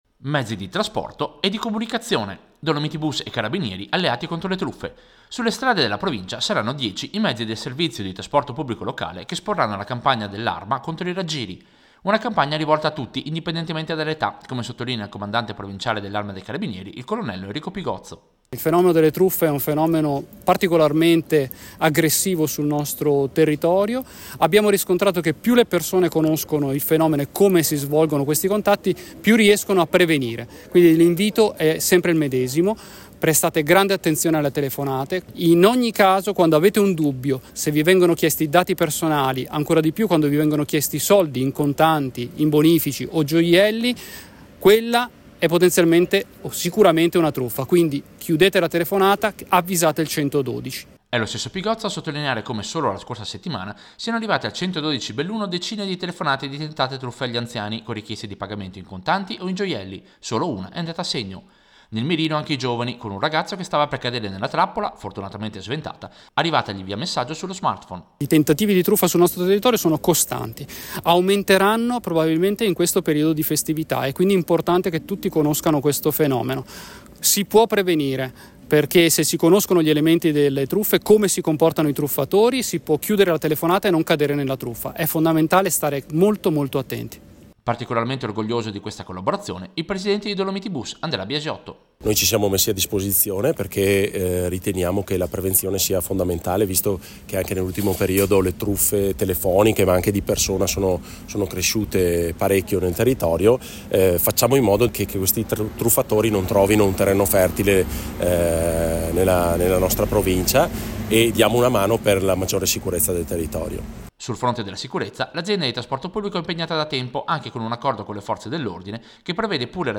Servizio-DolomitiBus-antitruffa-Carabinieri.mp3